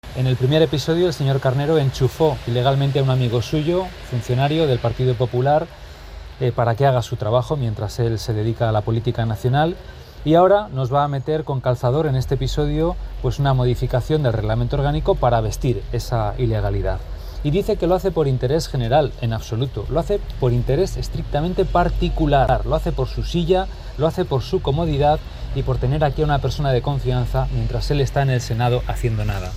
Pedro Herrero, portavoz del grupo municipal socialista en Valladolid, critica la modificación del reglamento orgánico para dar soporte legal al puesto de 'alcalde B'